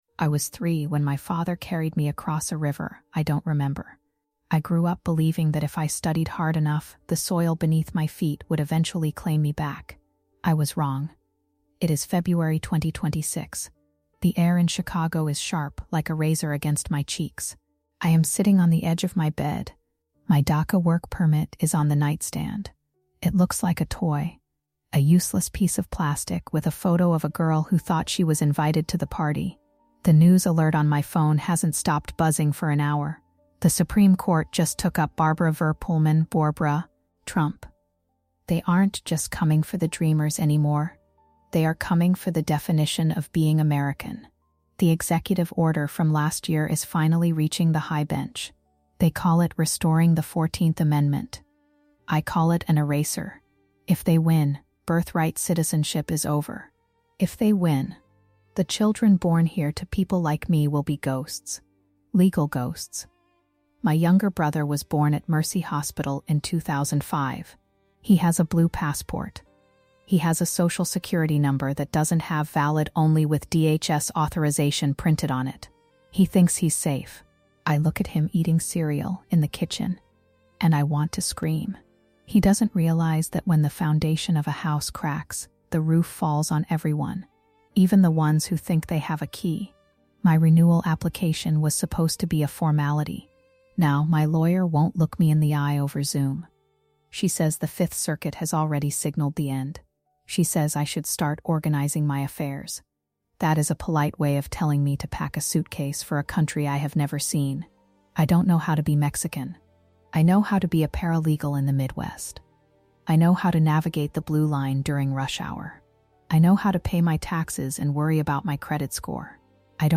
The 2026 Supreme Court challenges to birthright citizenship and DACA renewals are changing the meaning of home for millions of Dreamers. In this emotionally immersive episode of THE TRIALS OF WOMAN, a Mexican-American paralegal in Chicago shares her raw first-person story as she navigates the fallout of Barbara v. Trump and the sudden reversal of her legal standing in the United States.